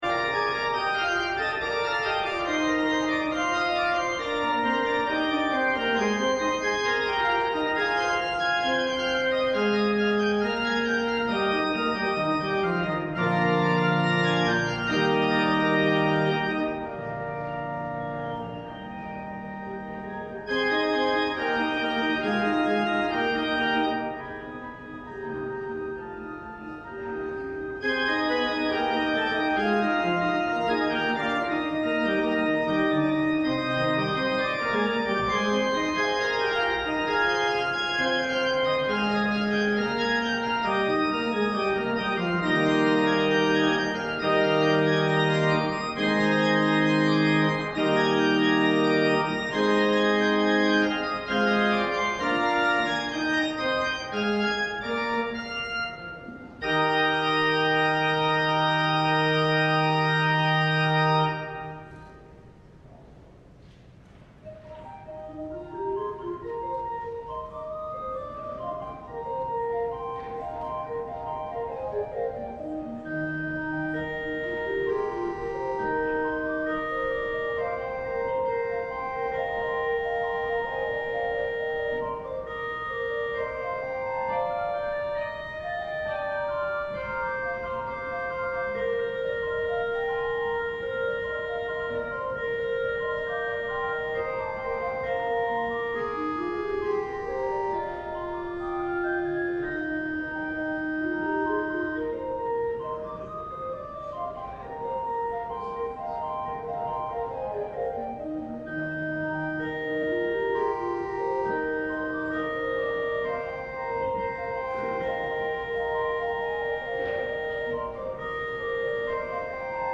LIVE Evening Worship Service - Let There Be Light